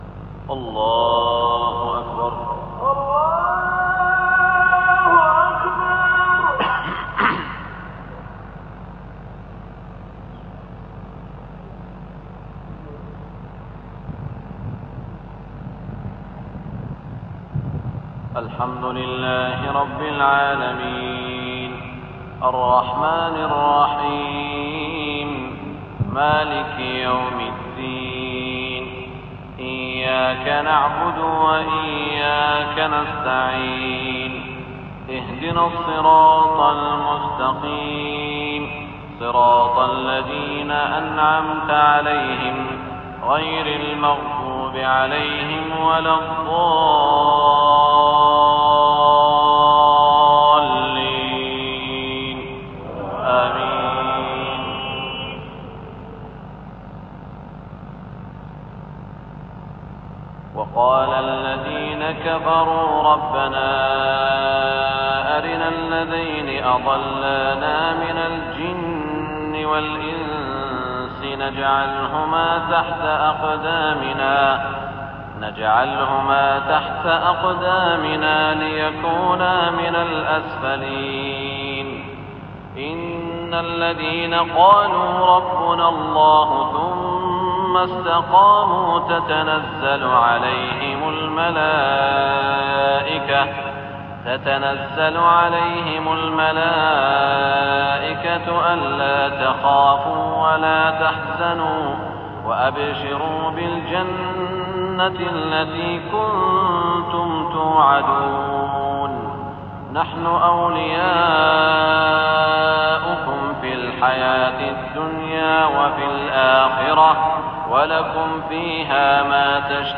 صلاة العشاء 1420 من سورة فصلت > 1420 🕋 > الفروض - تلاوات الحرمين